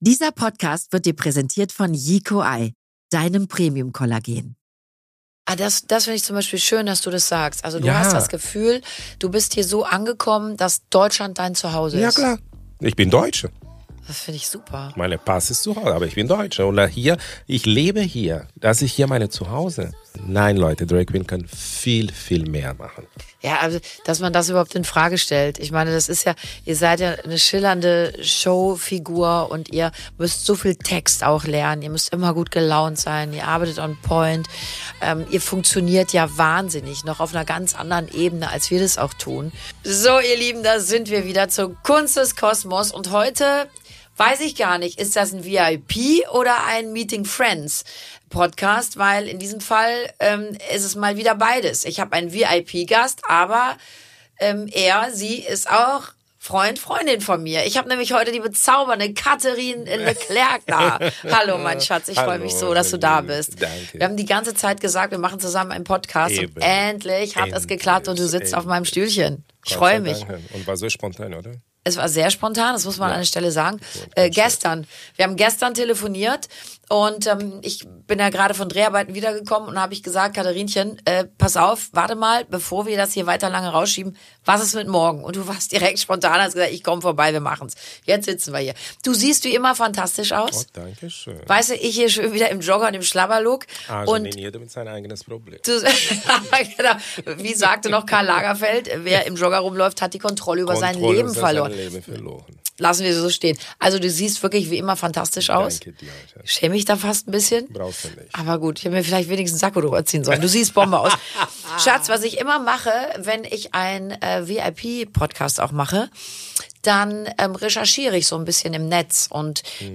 Ein Gespräch über Liebe, über Akzeptanz und darüber, dass das Leben am schönsten wird, wenn man sich selbst erlaubt, echt zu sein.